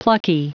Prononciation du mot plucky en anglais (fichier audio)
Prononciation du mot : plucky